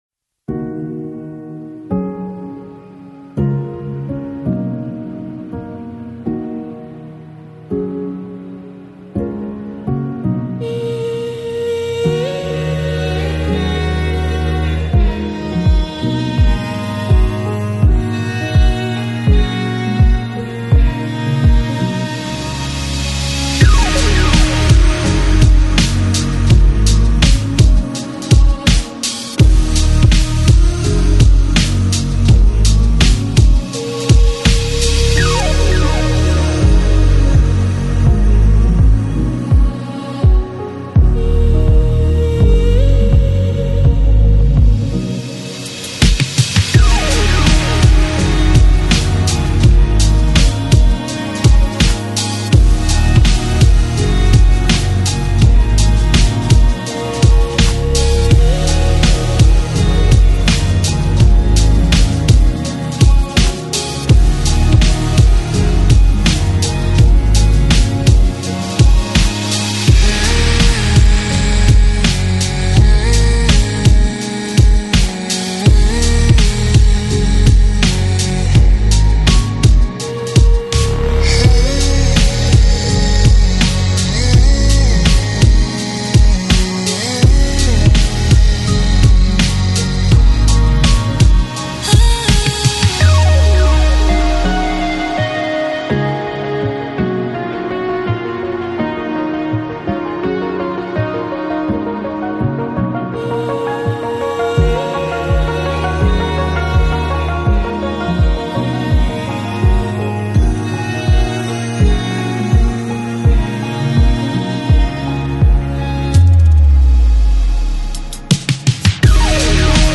2022 音乐流派: Electronic, Downtempo, New Age 厂 牌